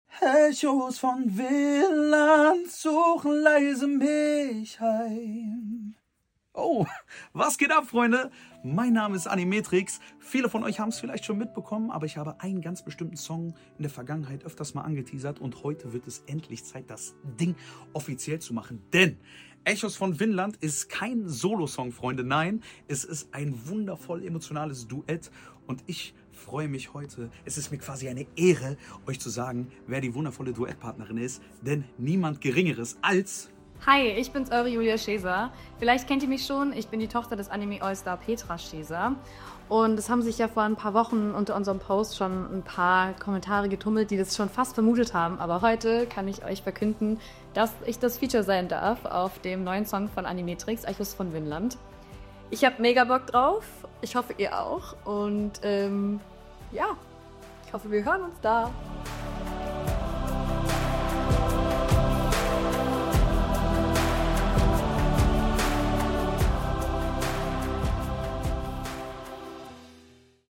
getrieben von purer Emotion